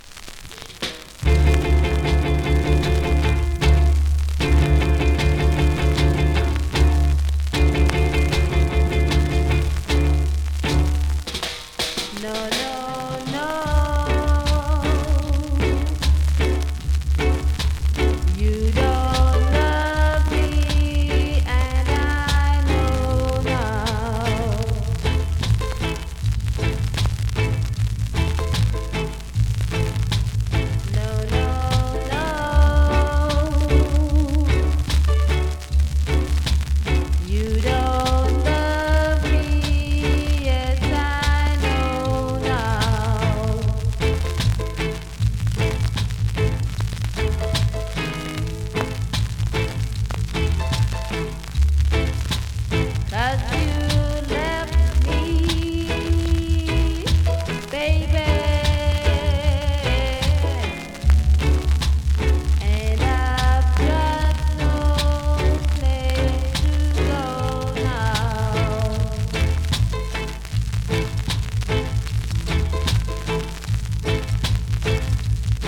2024!! NEW IN!SKA〜REGGAE
スリキズ、ノイズ比較的少なめで